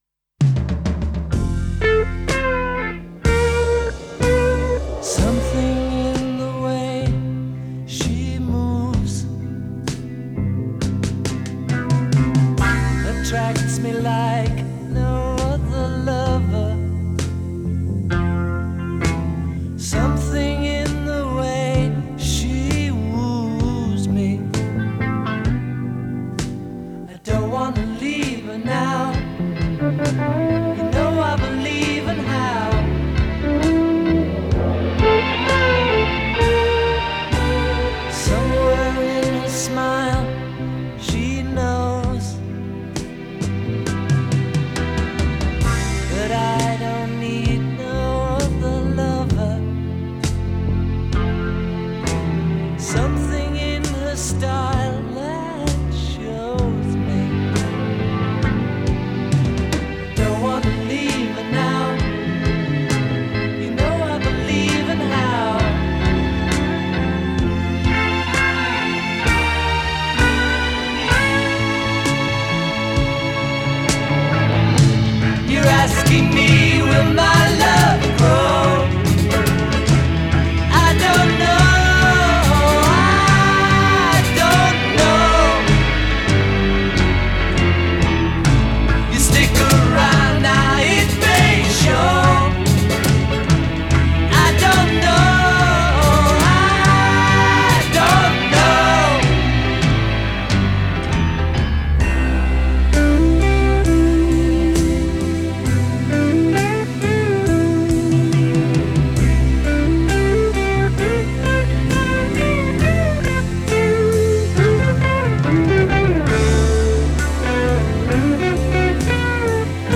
موسیقی راک